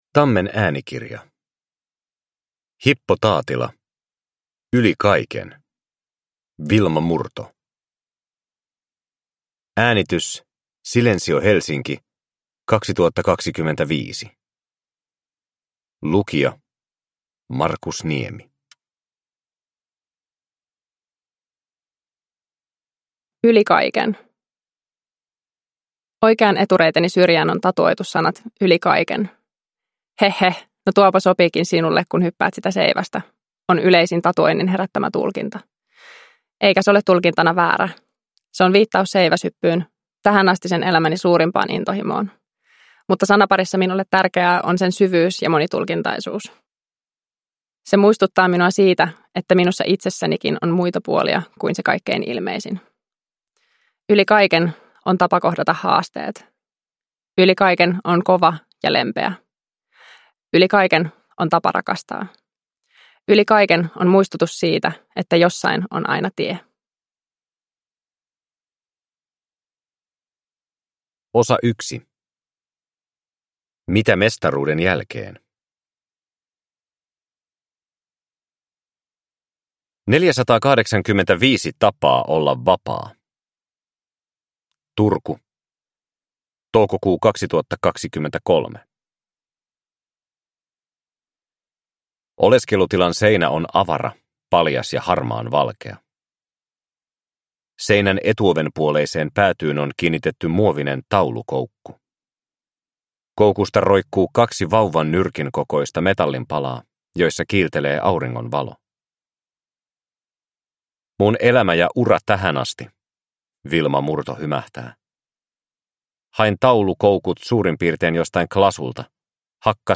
Yli kaiken - Wilma Murto – Ljudbok